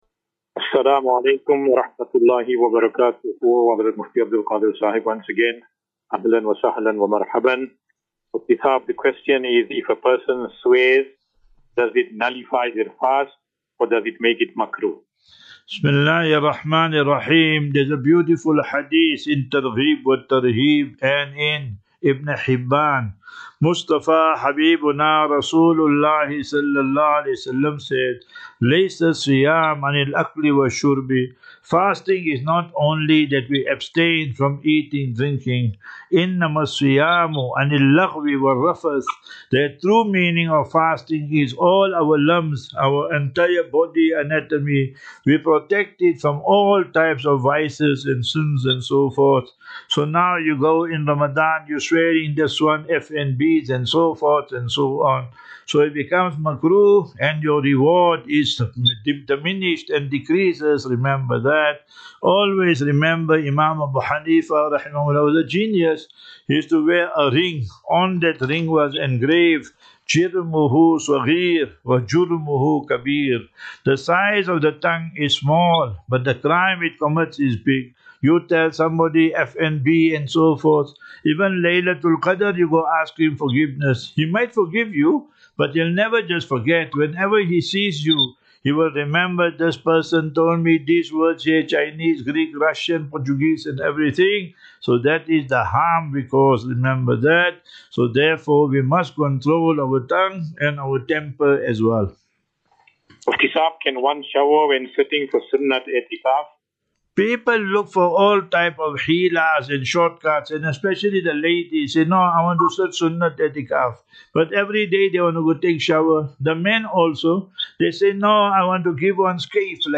View Promo Continue Install As Safinatu Ilal Jannah Naseeha and Q and A 26 Mar 26 March 2025.